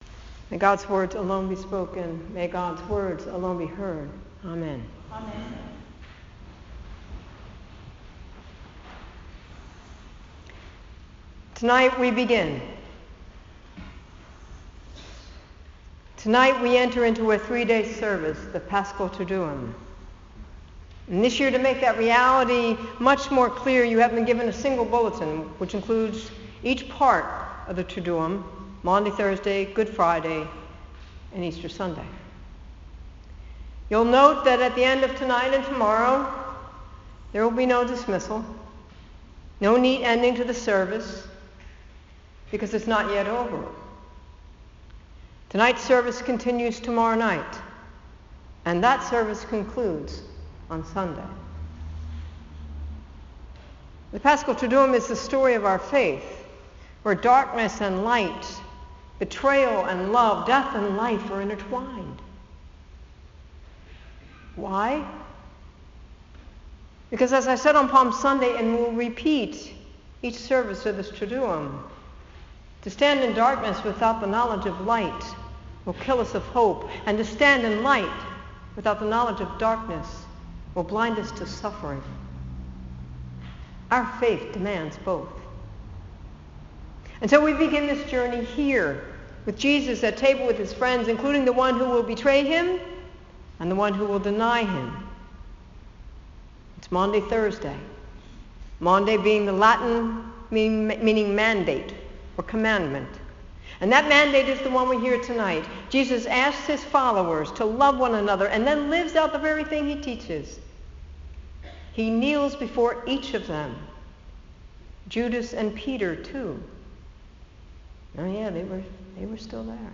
Maundy Thursday